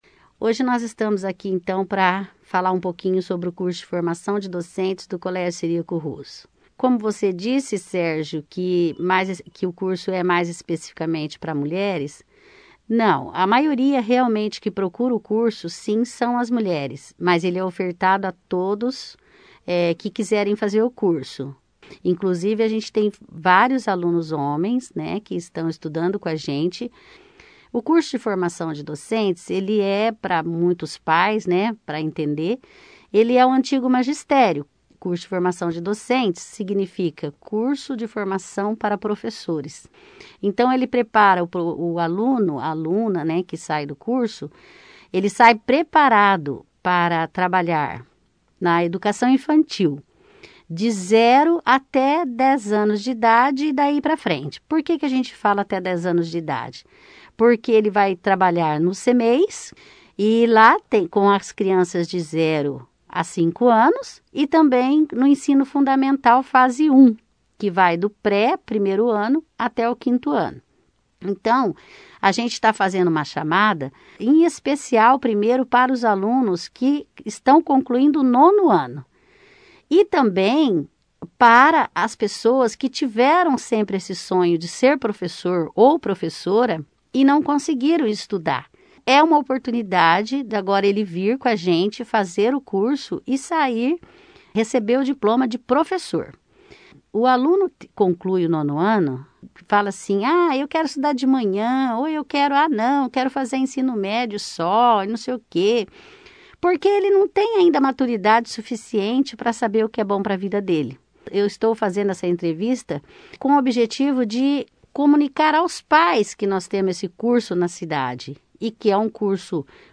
participou da 1ª edição do jornal Operação Cidade desta sexta-feira, 06/11, falando sobre as matrículas.